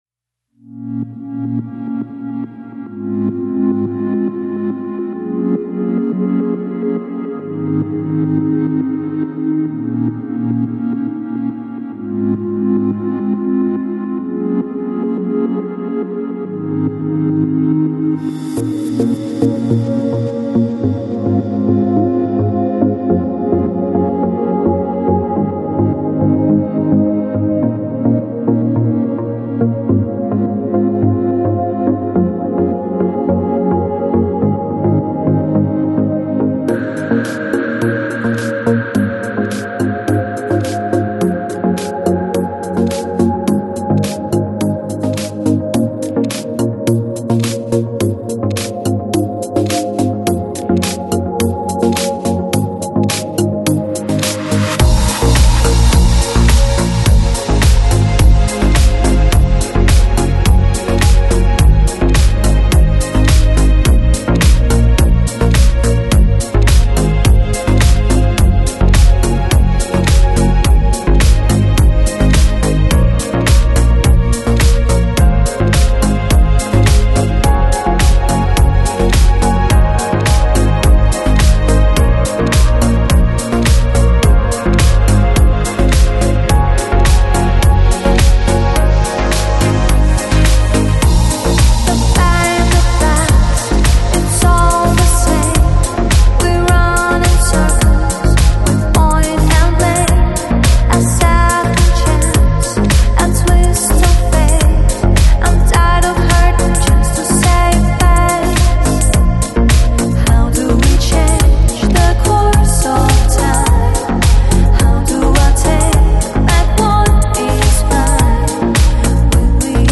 Lounge, Downtempo, Chill Out, Electronic Год издания